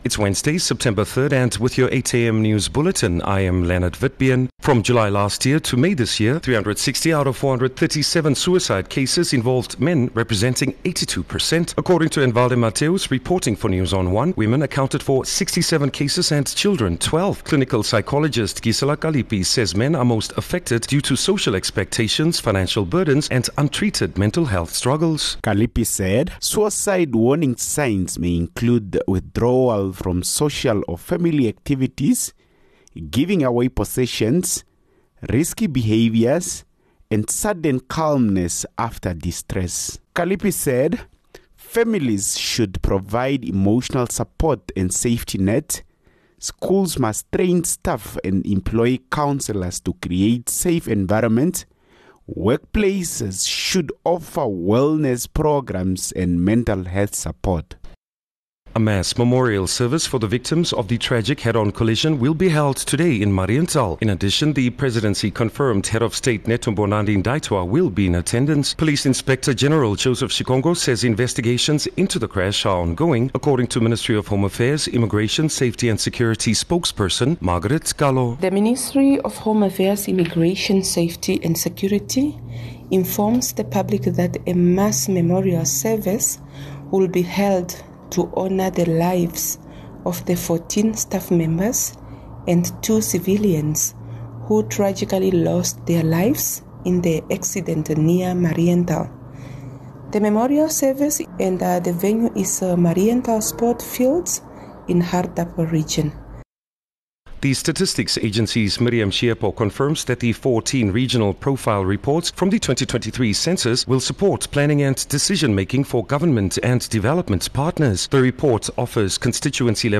3 Sep 3 September-8am news